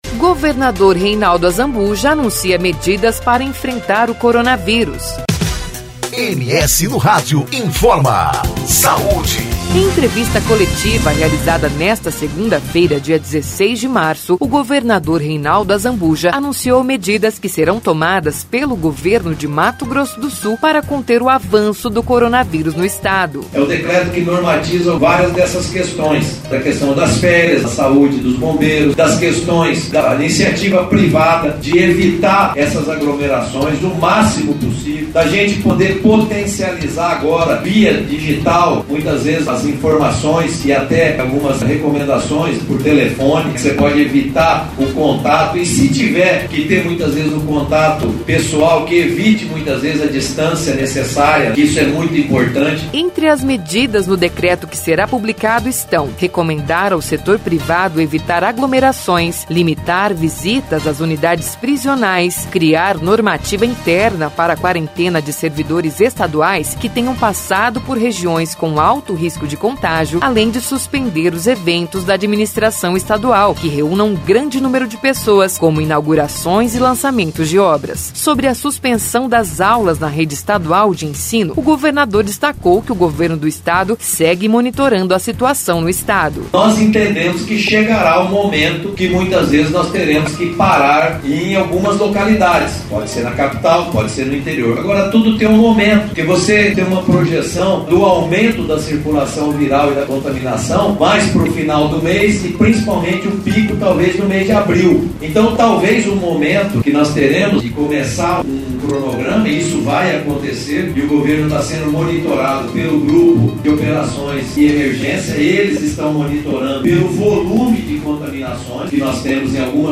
Campo Grande (MS) – Em entrevista coletiva realizada nesta segunda-feira (16.3) o governador Reinaldo Azambuja anunciou medidas que serão tomadas pelo Governo de Mato Grosso do Sul para conter o avanço do coronavírus no Estado.